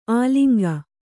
♪ āliŋga